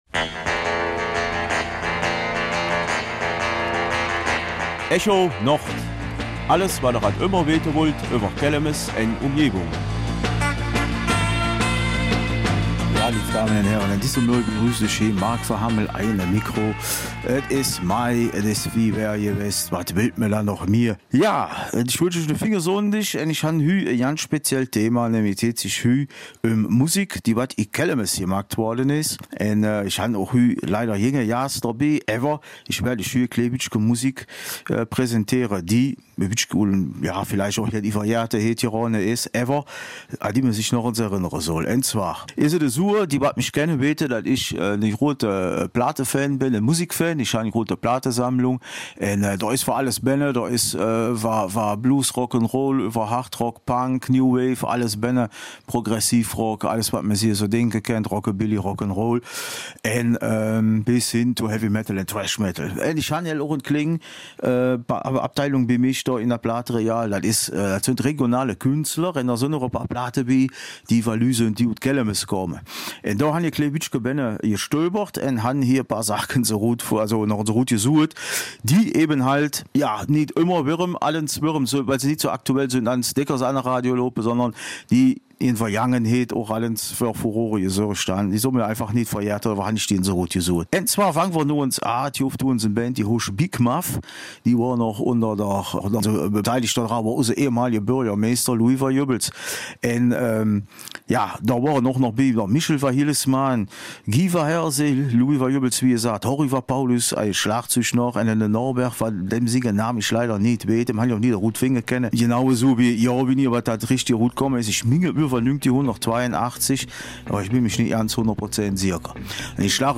In der Vergangenheit gab es einige Künstler, die ihr Schaffen durch Aufnahmen verewigten. Vier davon, die man heute nicht mehr so oft hört, werden vorgestellt. Vier verschiedene Lieder, vier verschiedene Stile.